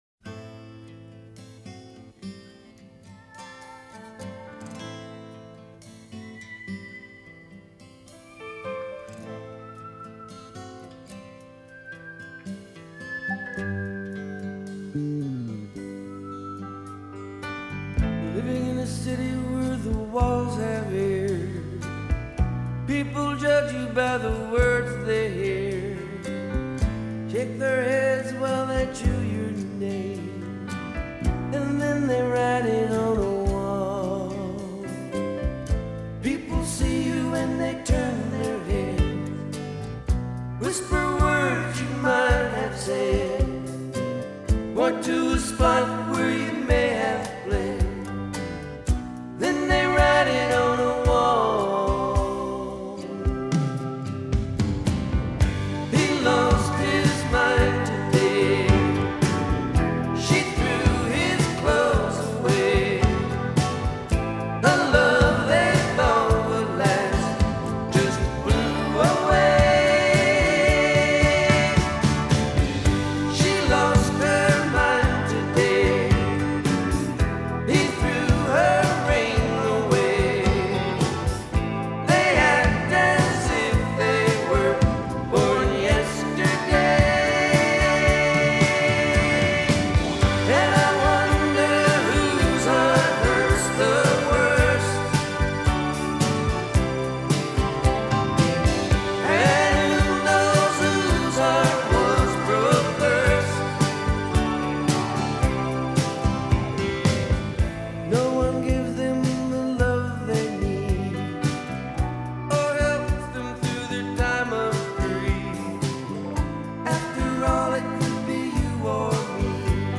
a great pop/country album